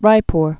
(rīpr)